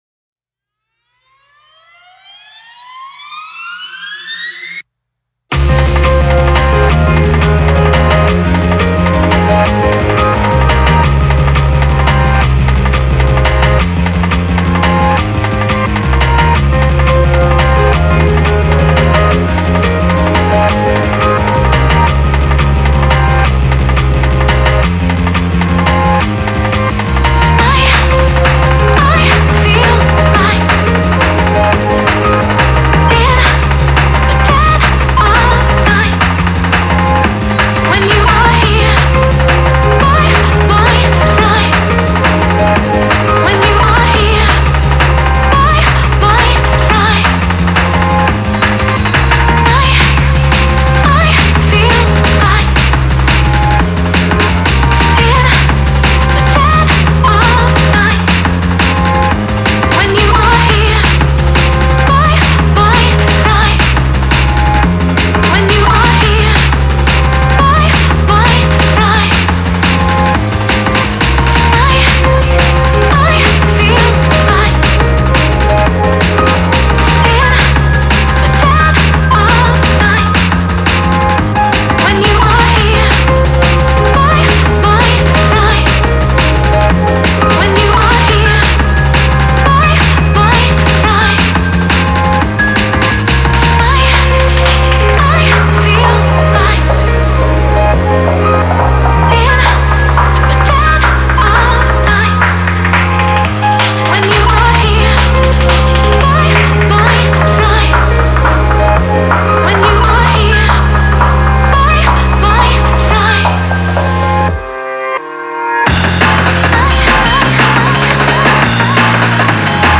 こちらはマスタリング前のBitwig Studio2で自分でミックスしたデモ音源です。フリーBGM版と同じ音源です。